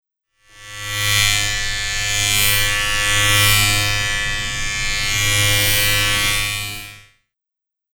Звуки электробритвы
Этот резкий, монотонный гул с вибрацией идеально подходит для создания звуковых эффектов в видео, ASMR-записей или использования в монтаже.
Гул работающей электробритвы